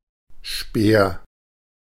Ääntäminen
Ääntäminen US UK : IPA : /spɪə̯(ɹ)/ Canada: IPA : /spɪɹ/ US : IPA : /spɪɹ/ Haettu sana löytyi näillä lähdekielillä: englanti Käännös Ääninäyte Substantiivit 1.